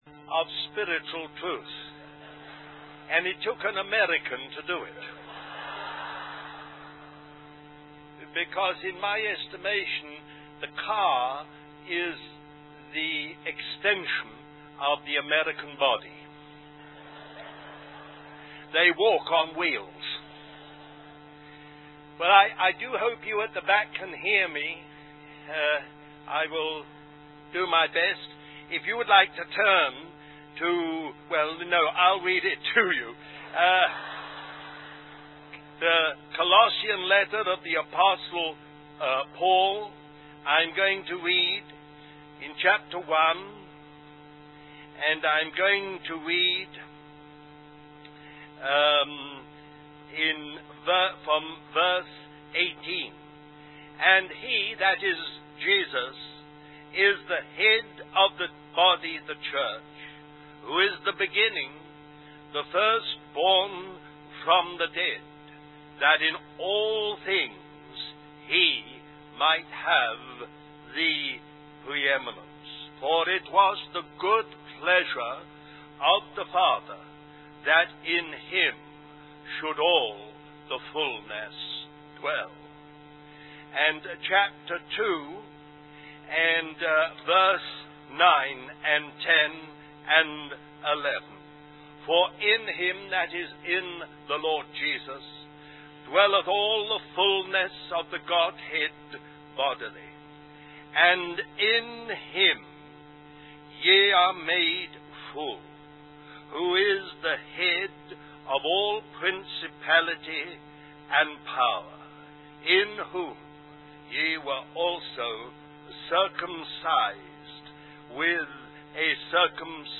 In this sermon, the speaker emphasizes the importance of believers growing and coming to full maturity. They highlight that the natural creation is longing for the manifestation of the children of God.